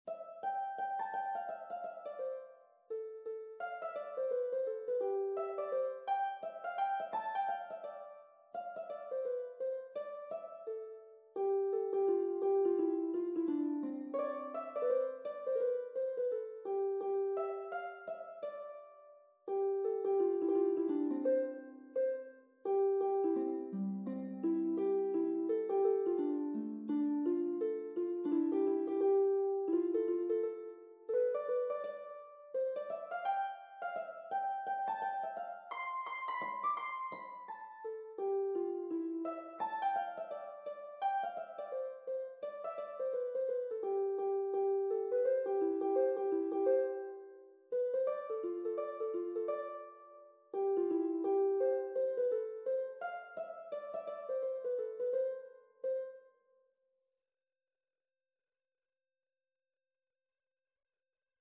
Traditional Turlough O Carolan Lady Blayney Harp version
C major (Sounding Pitch) (View more C major Music for Harp )
2/2 (View more 2/2 Music)
~ = 100 Allegro =170 (View more music marked Allegro)
Traditional (View more Traditional Harp Music)